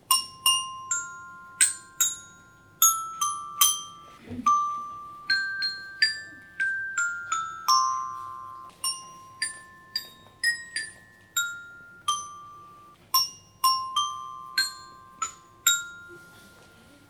Tous les 6ème ont enregistré le même morceau d'un chant de Noël " We wish you a merry Chistmas ".